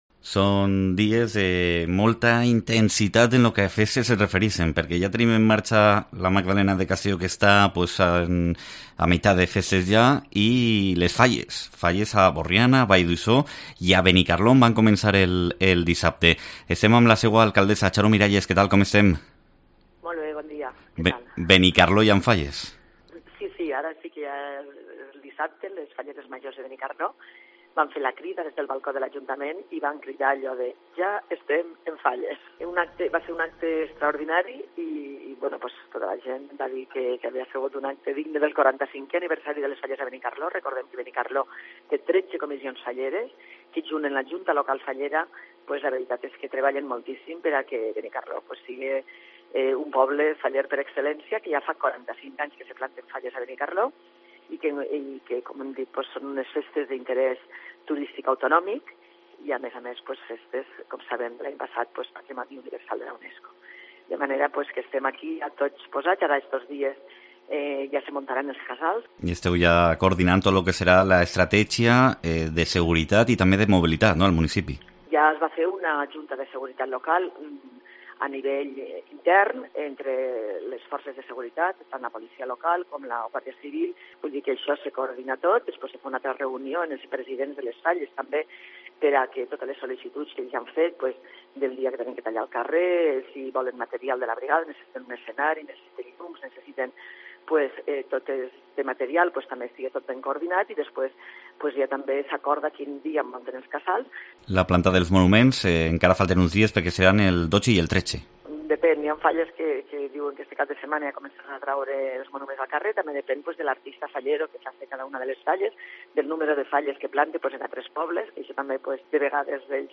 Entrevista a Xaro Miralles (alcaldessa de Benicarló)